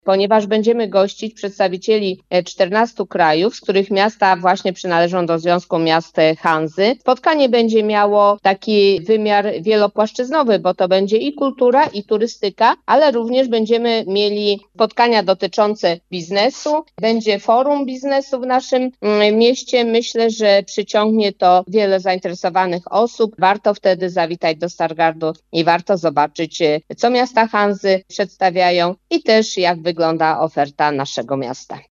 Jak podkreśla w rozmowie z Twoim Radiem Ewa Sowa, zastępca prezydenta miasta, będzie to ważne wydarzenie zarówno dla Stargardu, jak i całego regionu…